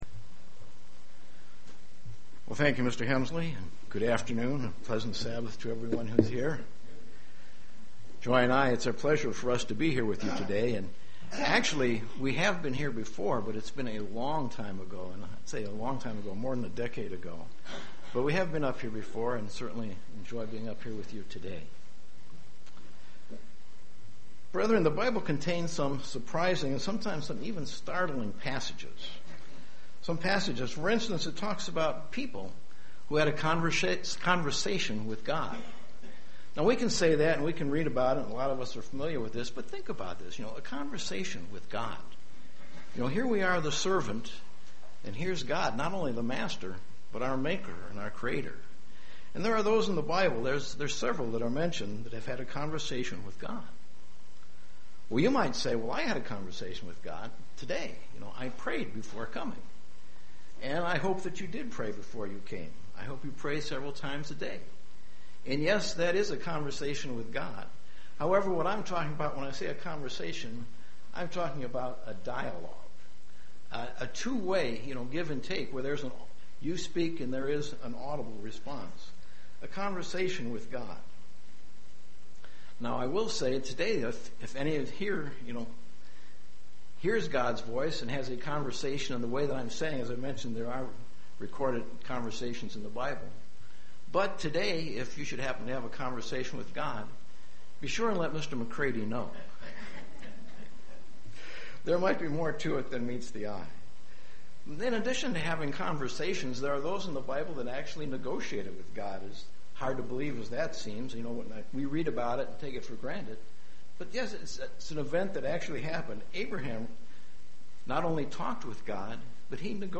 The Book of Habakkuk UCG Sermon Transcript This transcript was generated by AI and may contain errors.